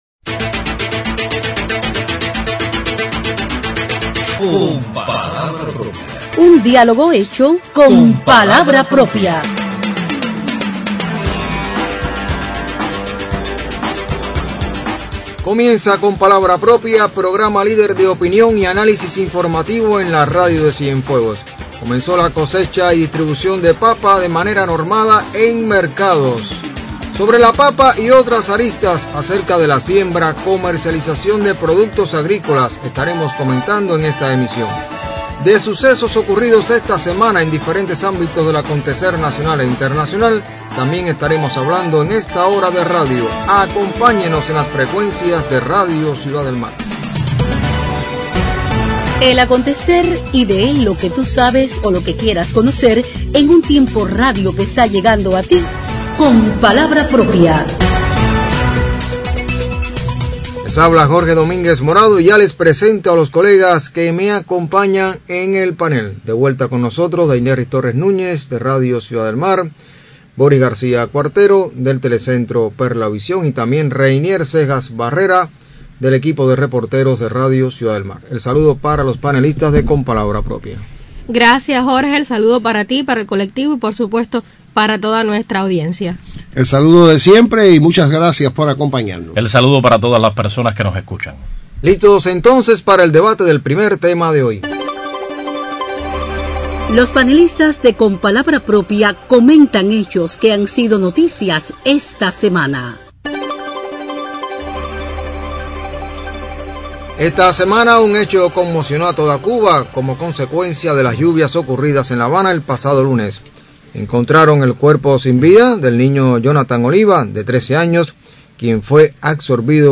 Sobre las lecciones que nos deja el fallecimiento de un niño en La Habana como consecuencia de las inundaciones comentan los panelistas de Con palabra propia.